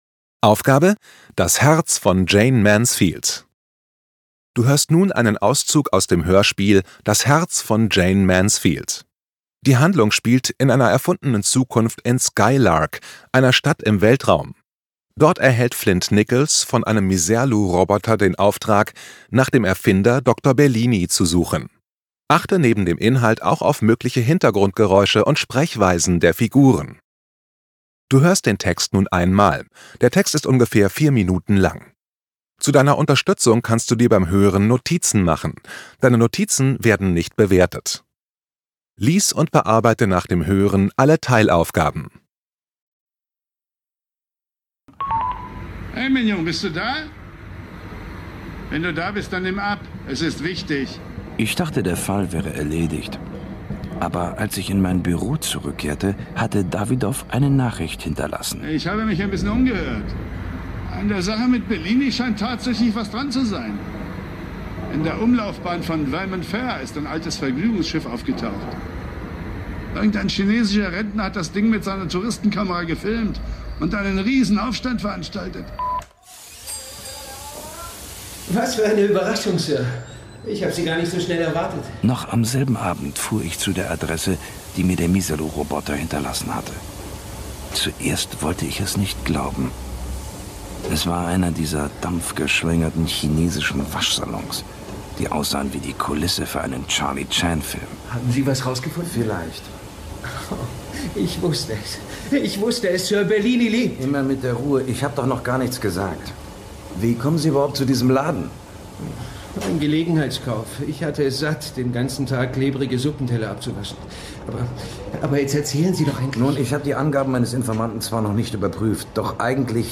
Hörspiel(-auszug)